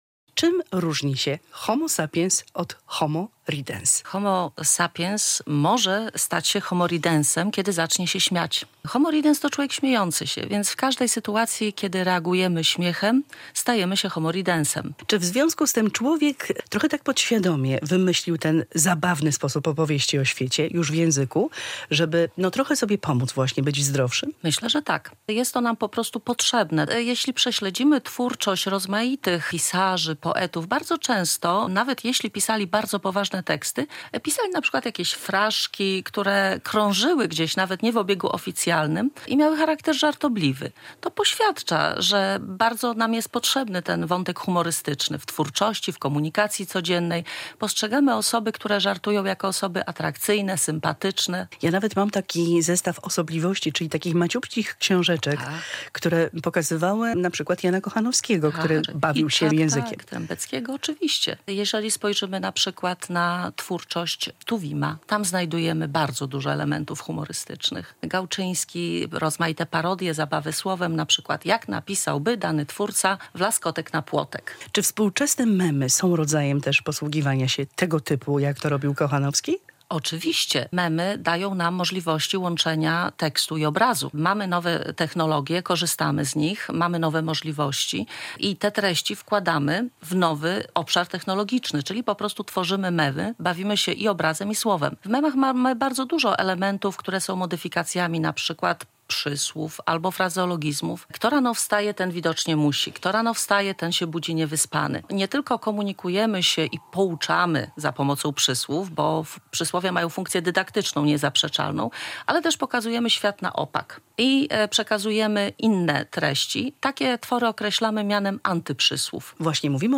Polskie Radio Białystok